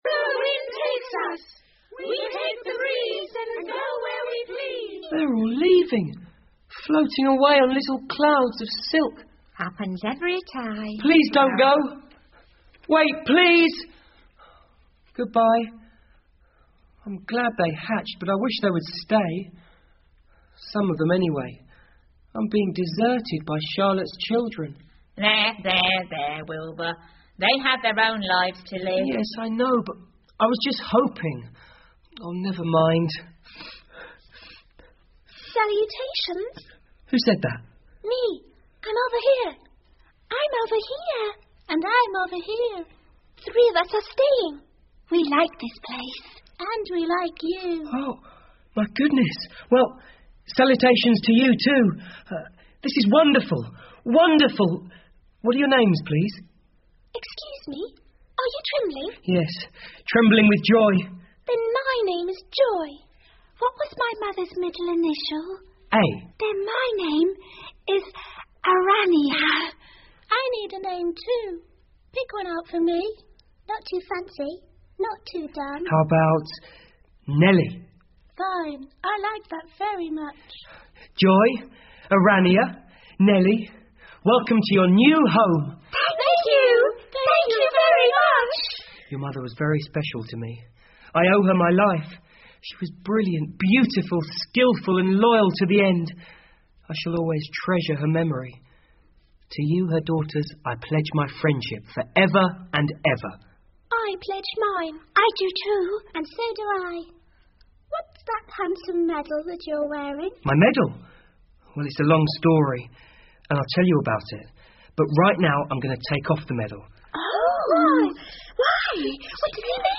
夏洛的网 Charlottes Web 儿童广播剧 18 听力文件下载—在线英语听力室